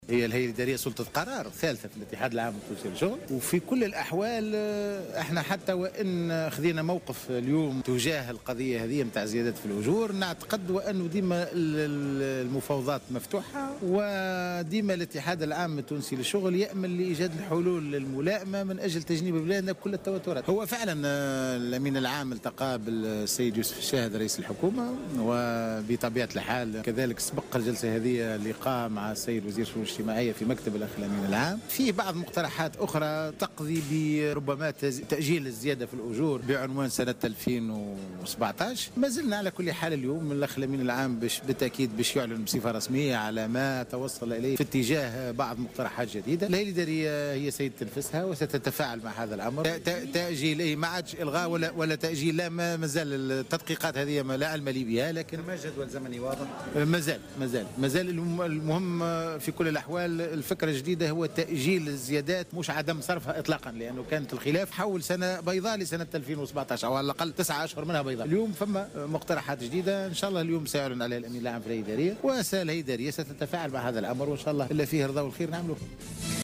وأضاف في تصريح لمراسلة "الجوهرة أف أم" أن الأمين العام للاتحاد حسين العباسي التقى أمس رئيس الحكومة يوسف الشاهد وتم اقتراح تأجيل الزيادة في الأجور بعنوان سنة 2017 بدلا عن إلغائها.